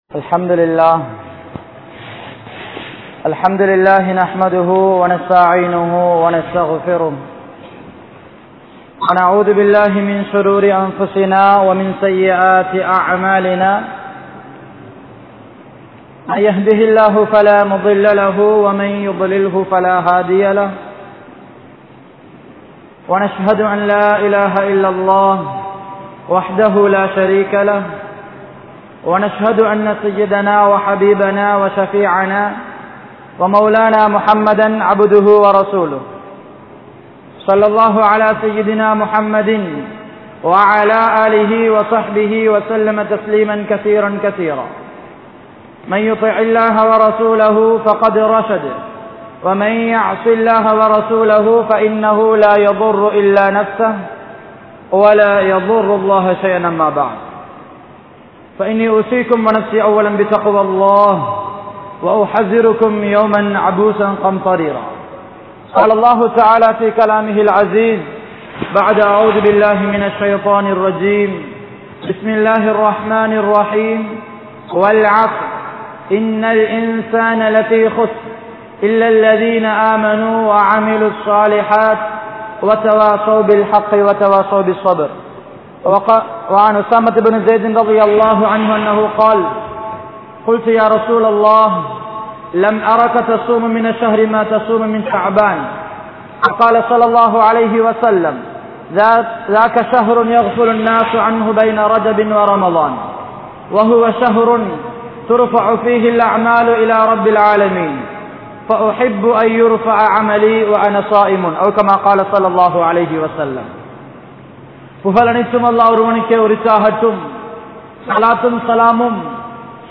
Ramalaanum Indraya Muslimkalum (ரமழானும் இன்றைய முஸ்லிம்களும்) | Audio Bayans | All Ceylon Muslim Youth Community | Addalaichenai
Grand Jumua Masjitth